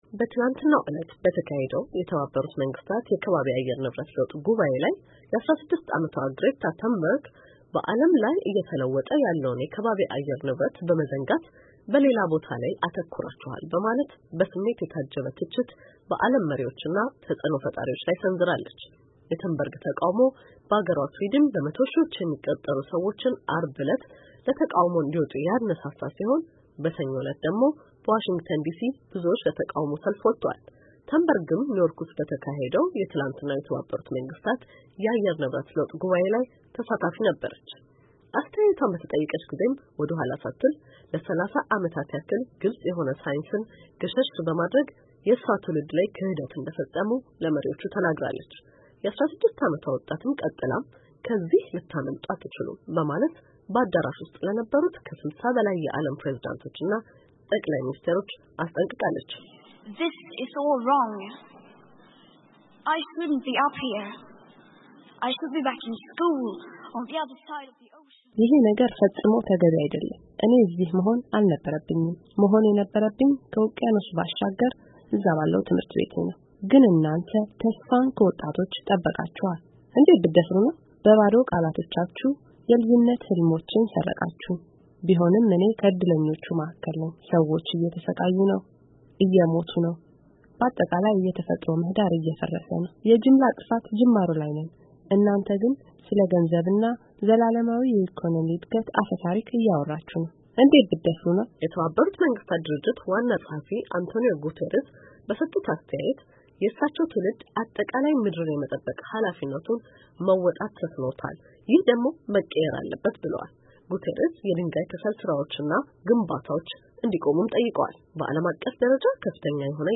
የ16 ዓመቷ ስዊዲናዊት የዓየር ንብረት ለውጥ ተሟጋች ግሬታ ተንበርግ በኒዎርክ ተገኝታ ከስልሳ በላይ ለሚሆኑ የዓለም መሪዎች ወቀሳዋን አድርሳለች፡፡ በዓየር ንብረት ለውጥ ላይ አሁኑኑ እርምጃ ካልወሰዱ የእሷ ትውልድ ይቅር እንደማይላቸው ተናግራለች፡፡